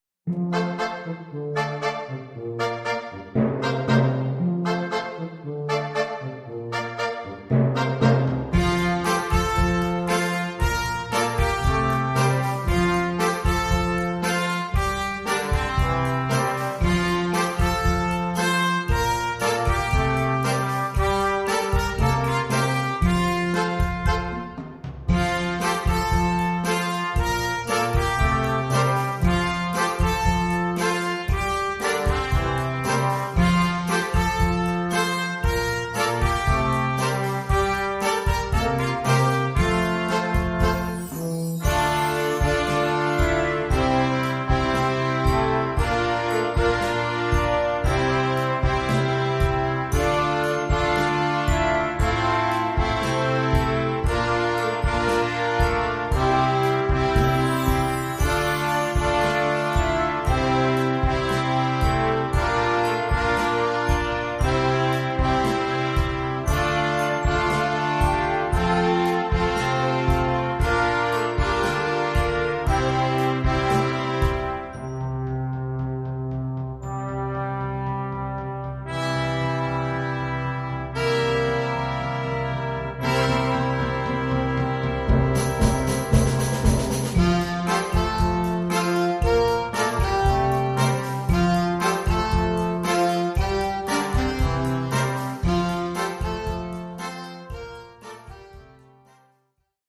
Oeuvre pour harmonie junior
Niveau : facile.